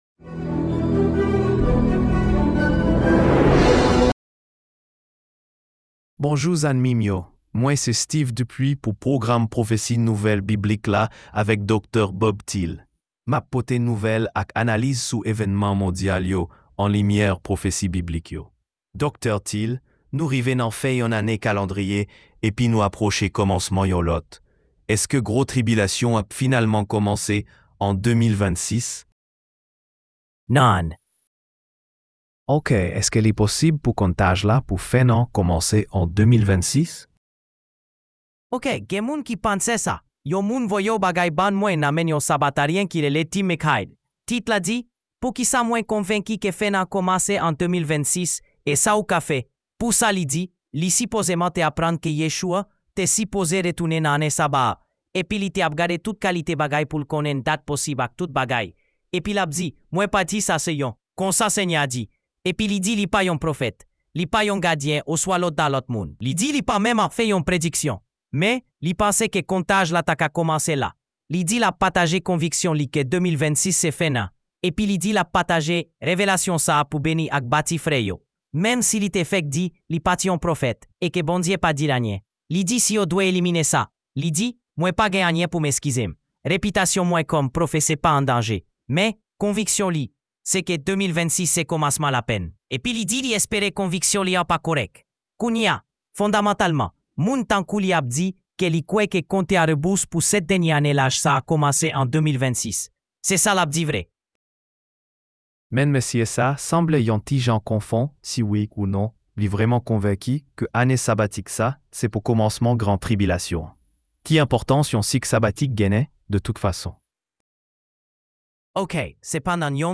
Haitian Creole Sermonette – Bible Prophecy News